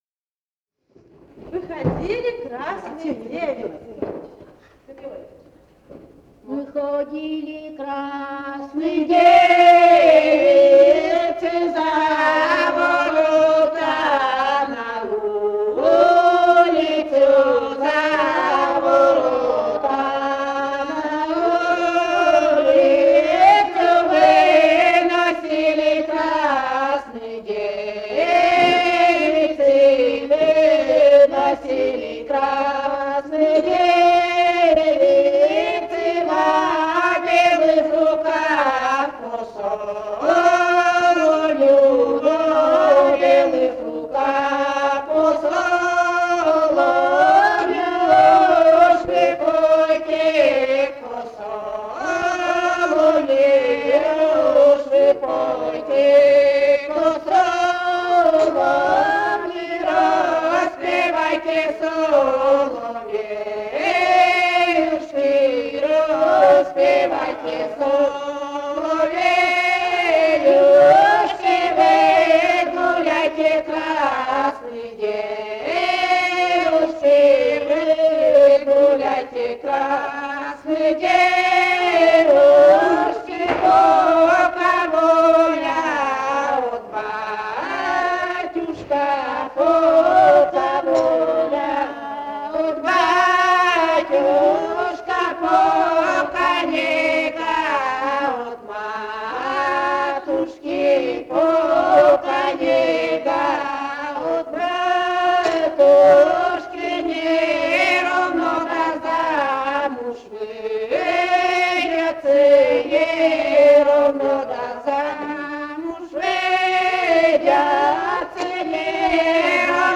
Живые голоса прошлого [[Описание файла::030. «Выходили красны девицы» (хороводная).